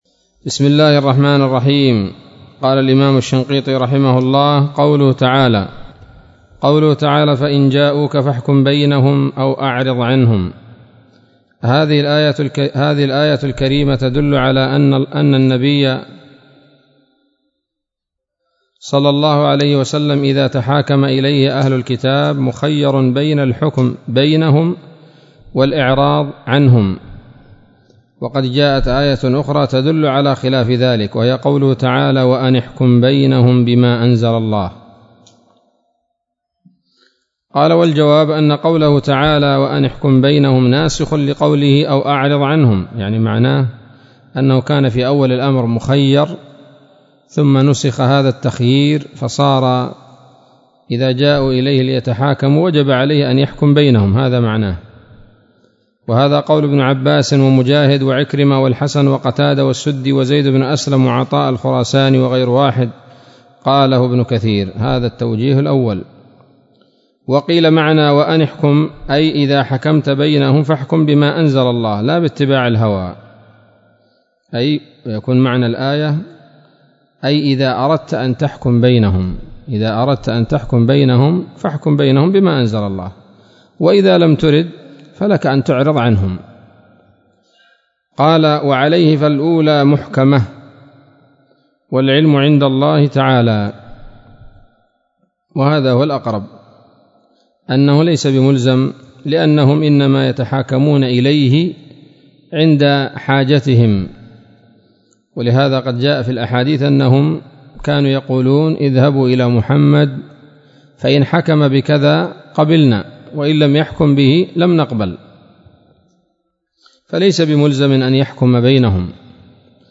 الدرس الثامن والثلاثون من دفع إيهام الاضطراب عن آيات الكتاب